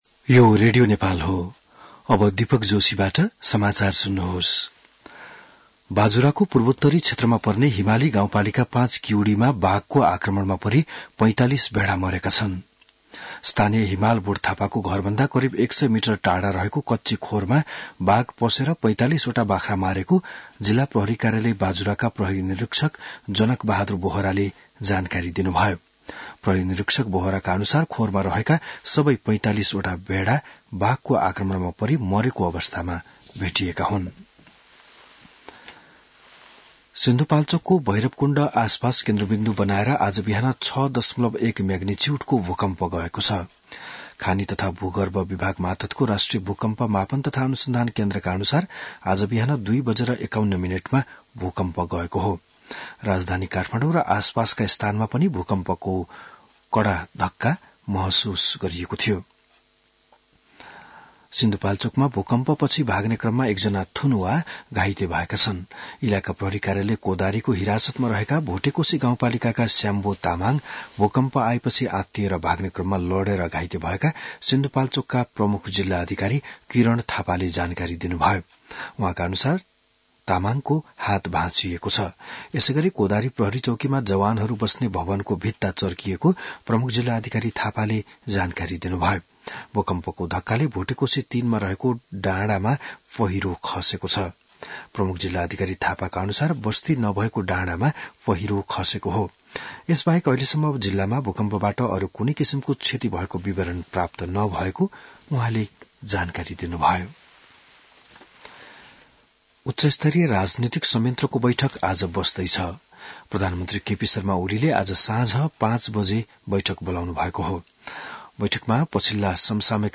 बिहान १० बजेको नेपाली समाचार : १७ फागुन , २०८१